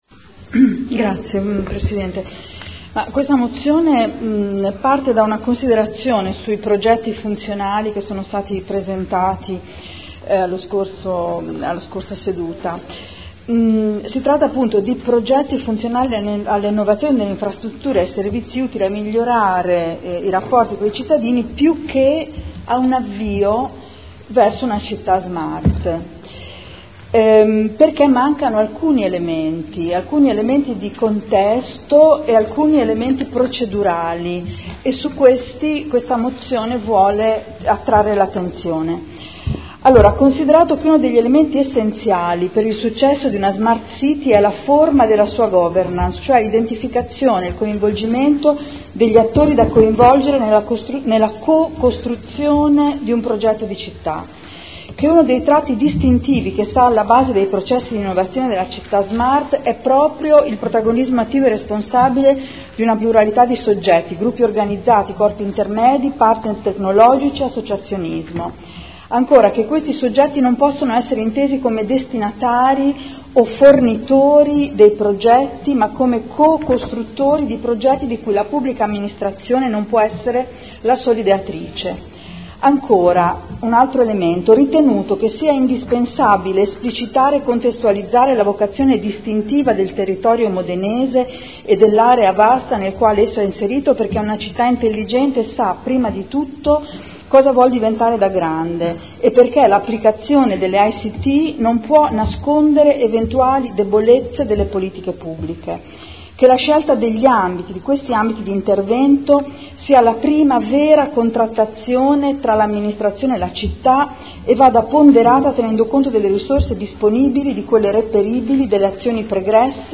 Seduta del 18 settembre. “PROGRAMMA SMART CITY” - Presentazione vari odg e Dibattito